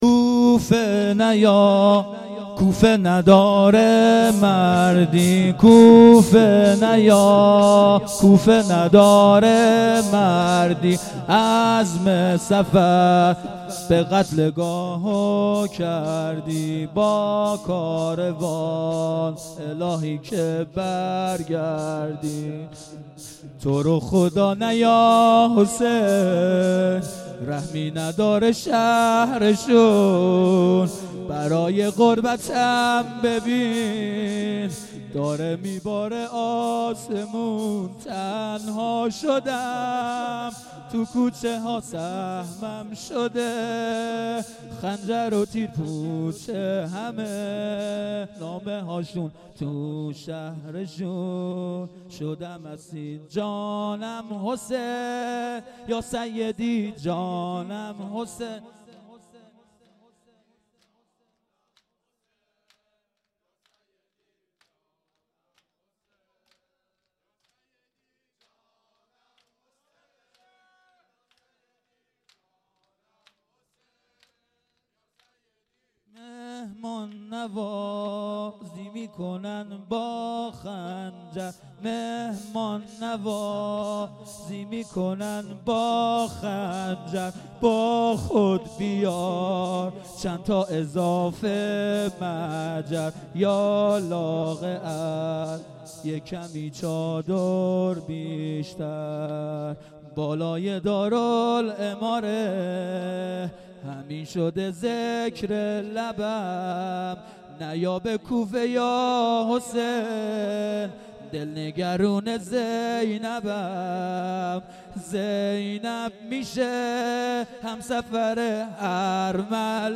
اصوات مراسم سیاهپوشان ودهه اول محرم۹۷هییت شباب الحسین